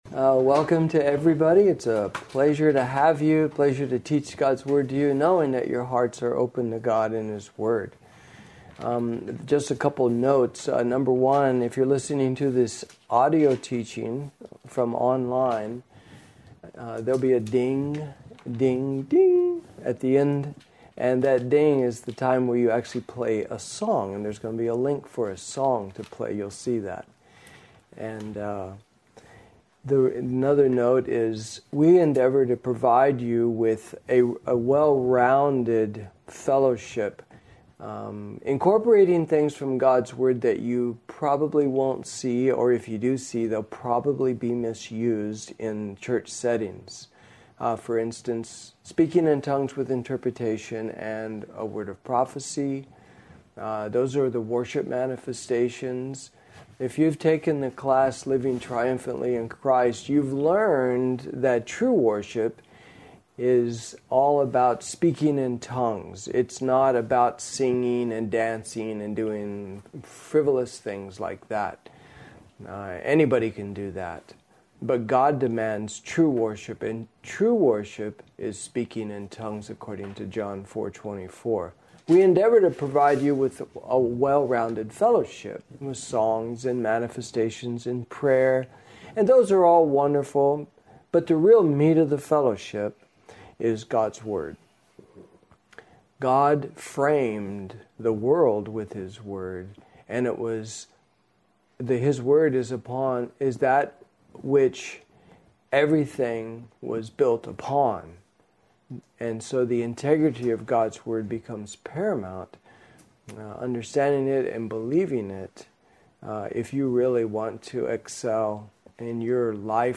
You will hear a musical tone at the end of the teaching to let you know when to play the song